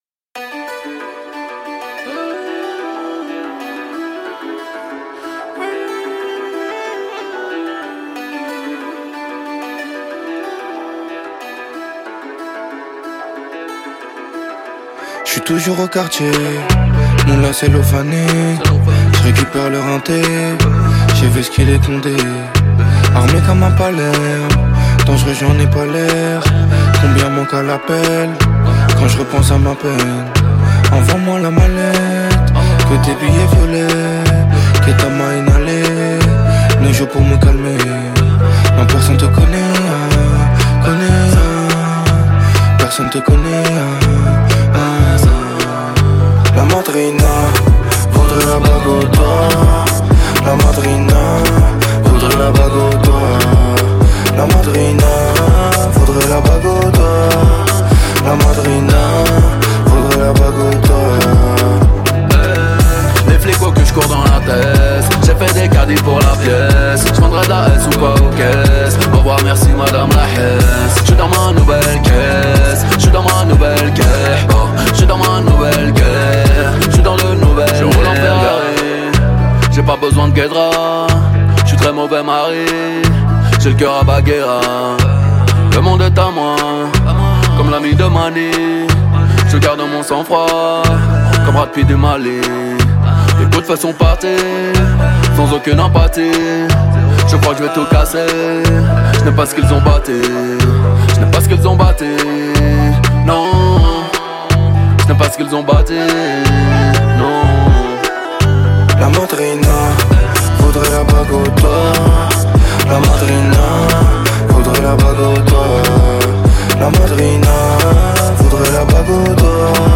64/100 Genres : french rap, pop urbaine Télécharger